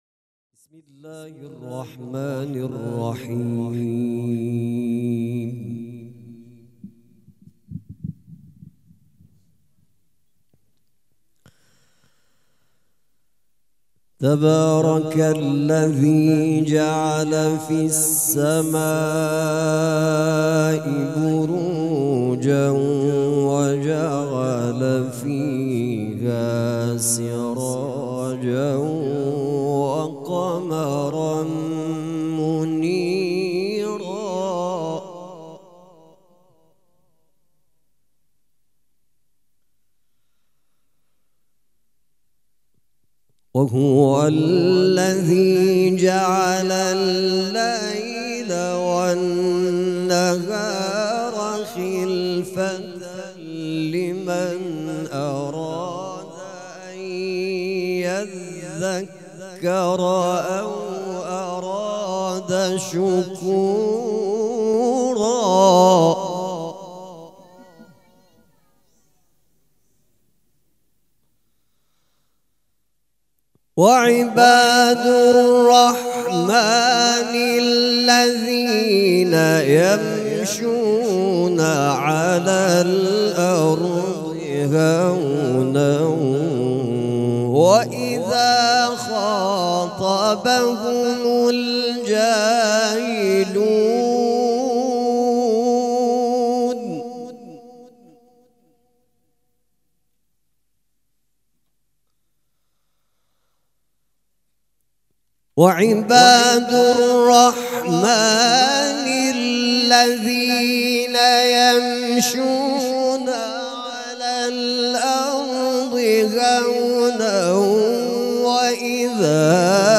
قرائت قرآن
مراسم عزاداری شب دوم